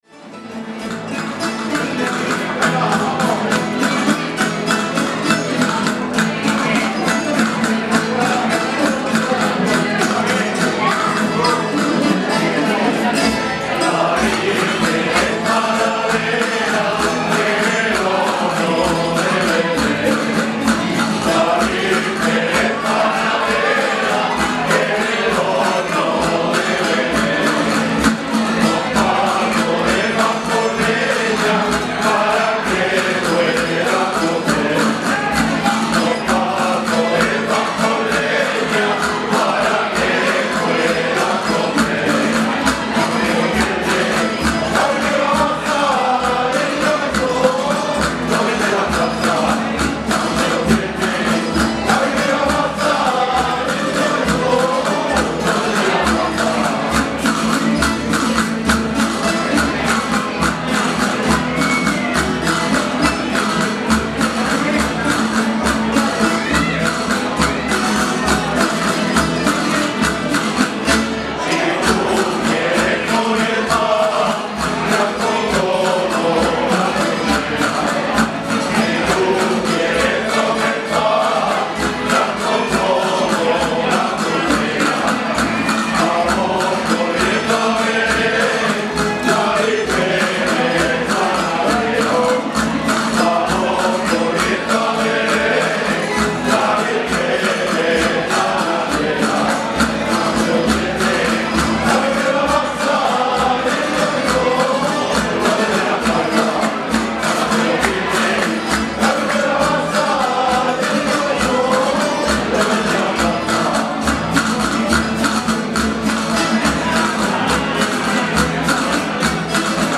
Además, hubo sorteo de regalos donados por comercios colaboradores, tartas con premio, mantellina y se contó con la actuación de cuadrillas.